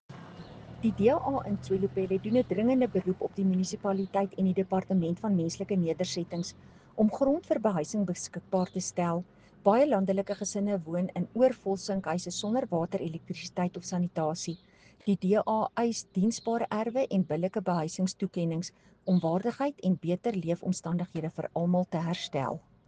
Afrikaans soundbites by Cllr Estelle Pretorius and